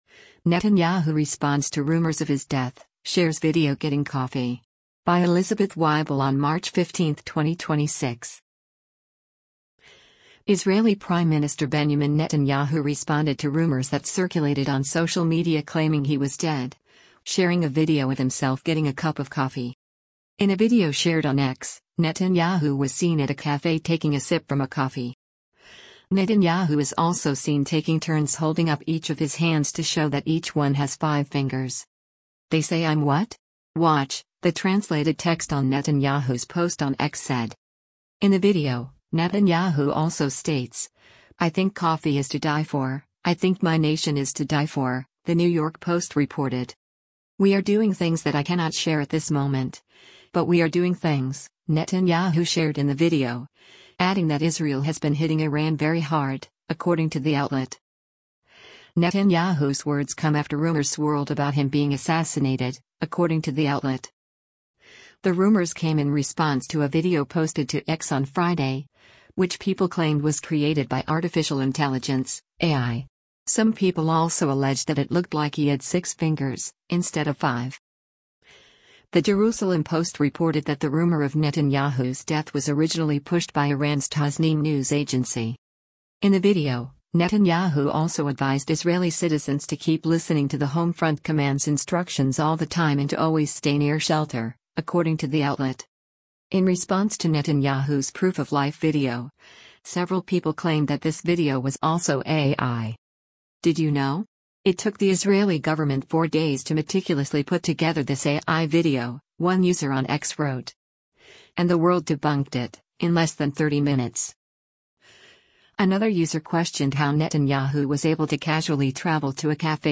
In a video shared on X, Netanyahu was seen at a cafe taking a sip from a coffee.